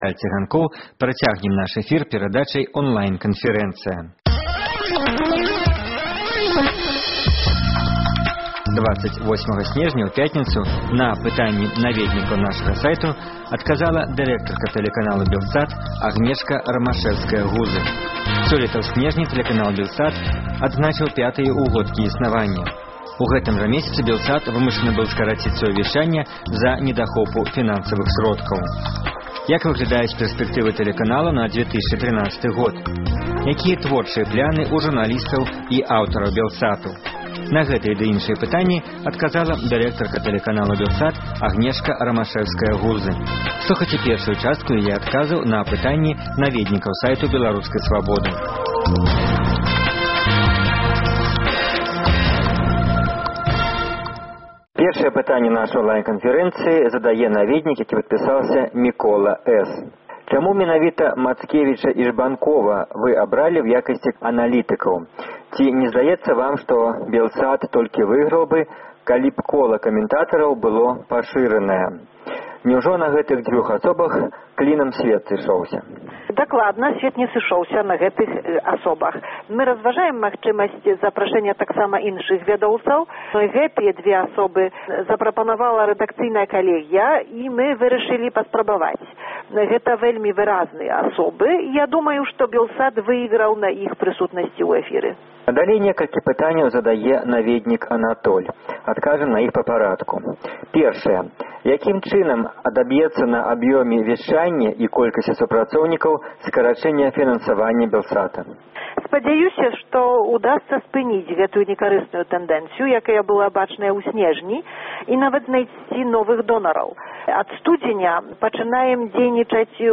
Радыёварыянт онлайн-канфэрэнцыі